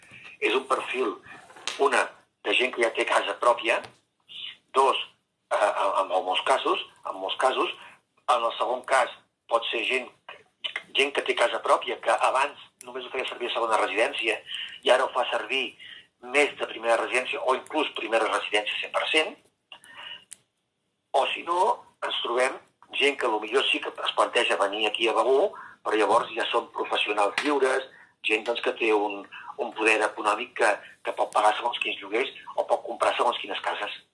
L’agència d’habitatge Finques Begur n’explica els avantatges: